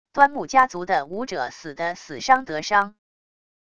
端木家族的武者死得死伤得伤wav音频生成系统WAV Audio Player